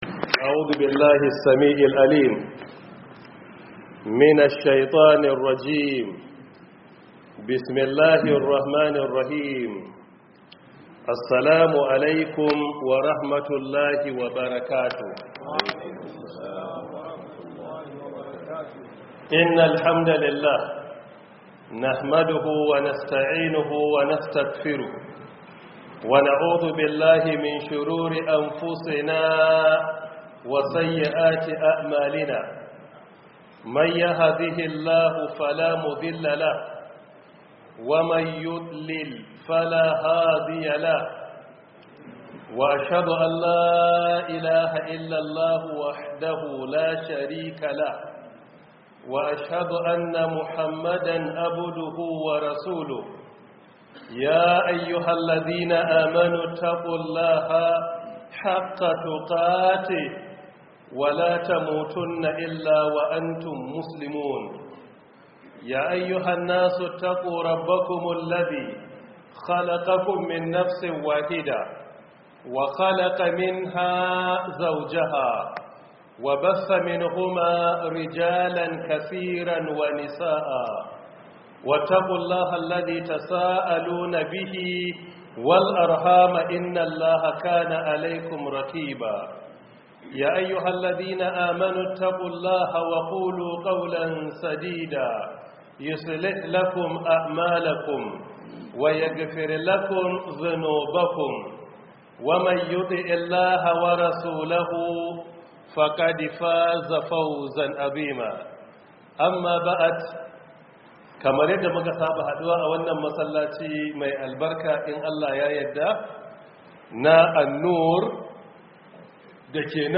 002 Prof Isa Ali Pantami Tafsir 2026